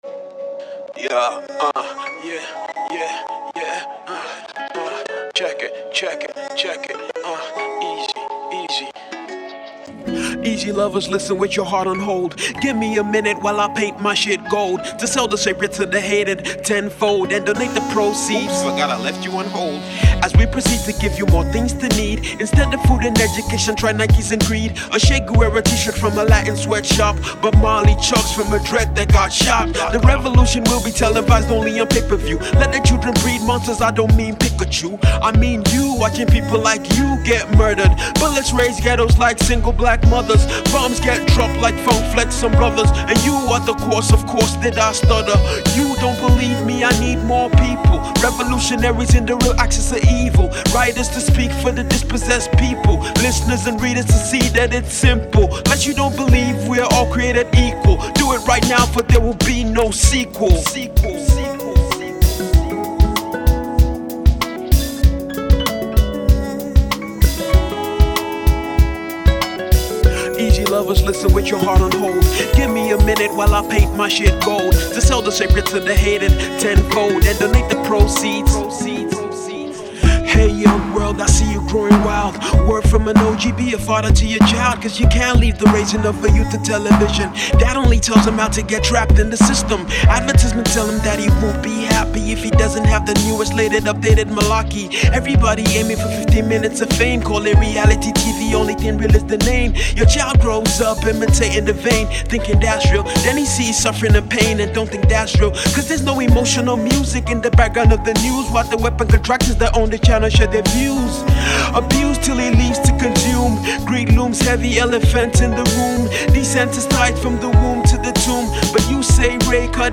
Note*recommended for Hip-Hop Heads…